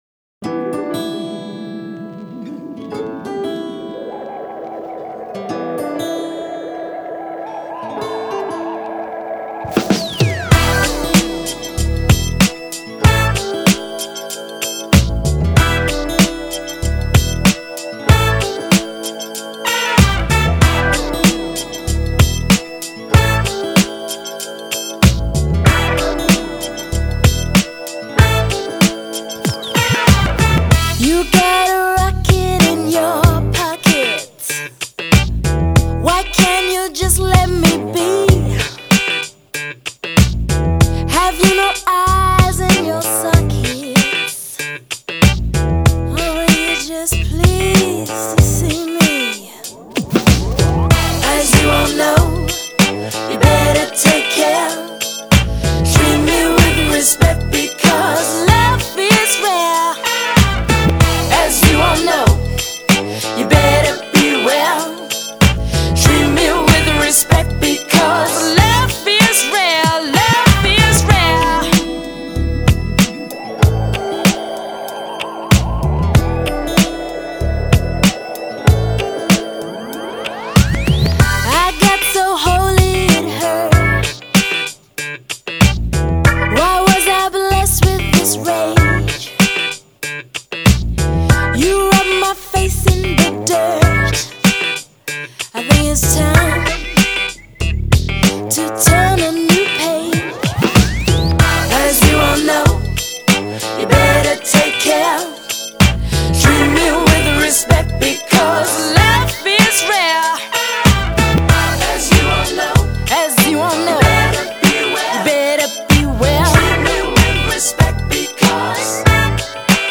音乐流派：Dance & DJ/Trip-Hop
迷幻的混音，沉实的节拍，再加上女主音慵懒性感的唱腔，一派时尚伦敦的都市气息。